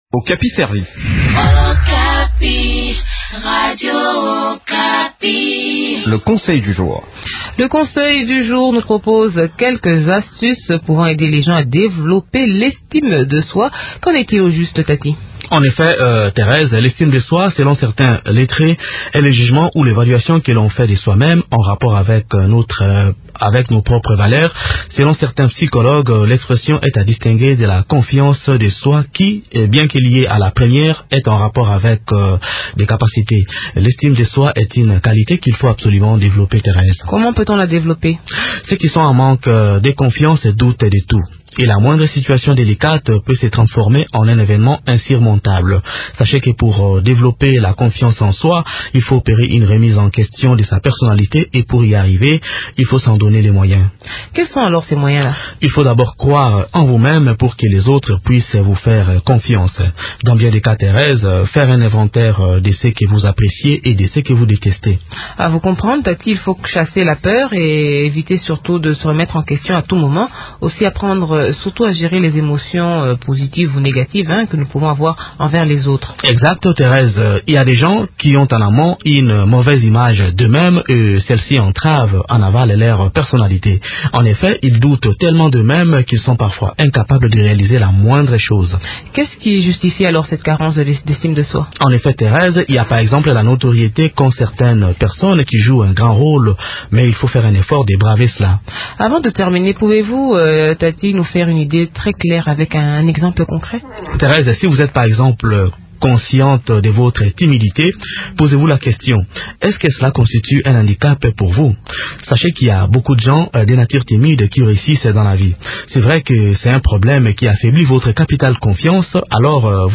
Sachez qu’il est possible de développer l’estime de soi. Pour y arriver, il faut, par exemple, opérer une remise en question de sa personnalité. D’autres précisions dans cette interview